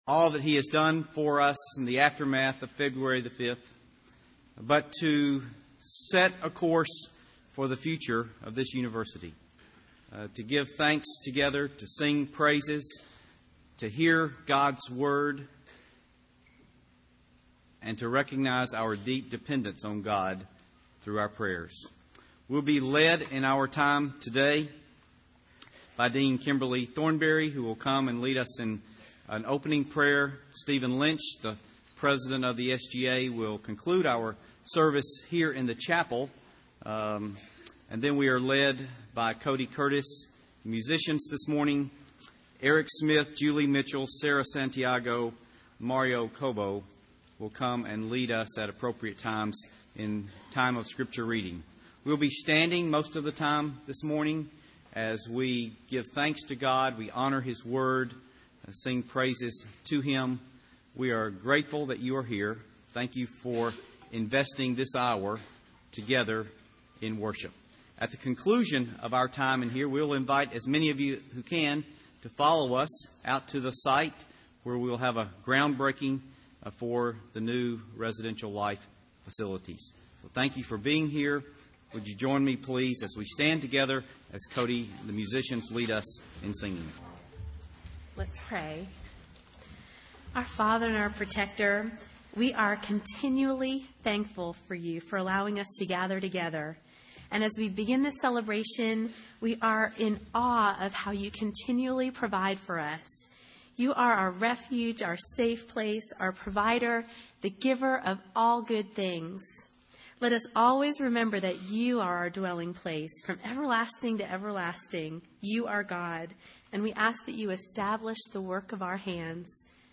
Campus-wide Worship Service: Dorm Groundbreaking